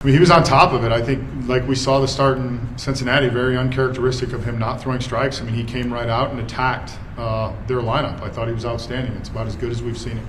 Manager Derek Shelton says Bailey Falter was unhittable.